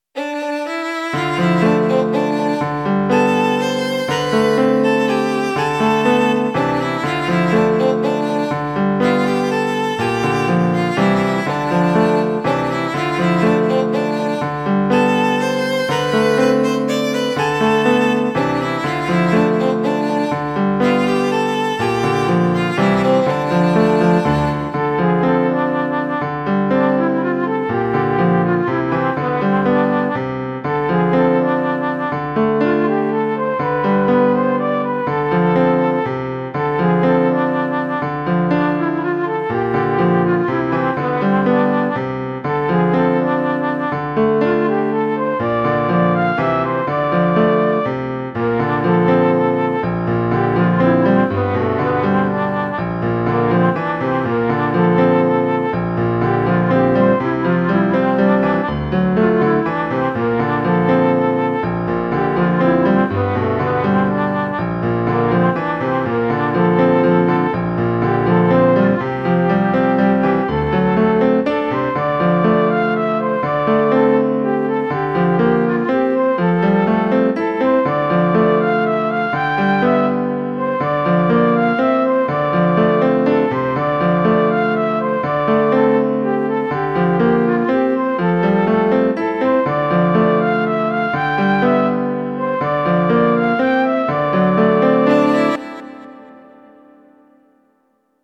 通常の４拍で作っても良いですし、３連符で作ってもいいのですが、色々と悩んで、今回は１小節３拍で行くことにしました。
曲のラフスケッチは、取りあえずはピアノとメロディラインのみ作っていきます。
前奏がバイオリンで、ボーカルメロディはフルートにしています。
ちなみに、キーは女性ボーカルだとＦ（ヘ長調）～G（ト長調）位が丁度良いのですが、今回はＦで作っています。
ラフスケッチでも気持ちよく作業したいので、簡単に音量や定位を調節してリバーブを加えています。
イントロは主題にもなる部分なので、しっとりと作っています。Aメロはそれを受けて淡々と、Ｂメロは抑え気味にして、最後はサビにつなげる為に盛り上がりをつけています。
サビは盛り上がる部分なので、振り切ってエモーショナルにしています。全体的に、所々で民族調っぽいコードを使っています。